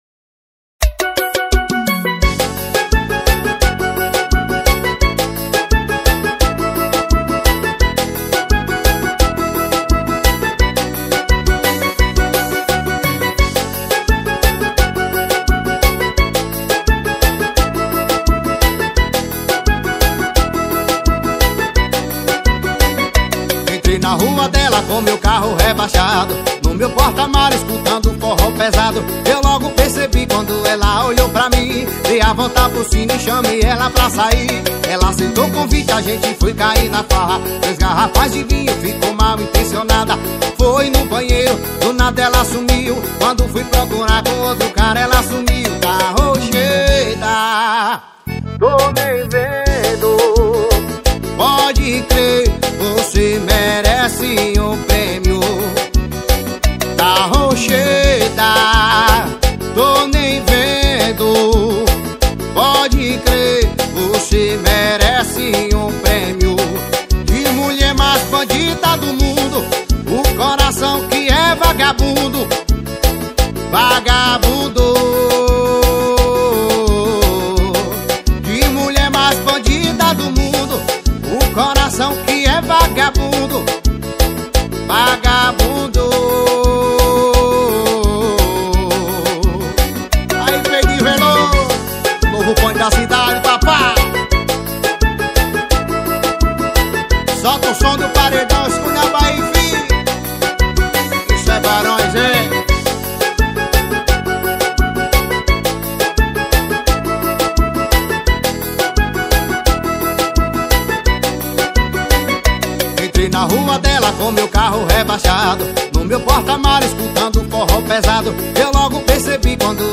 2024-10-09 04:34:34 Gênero: Forró Views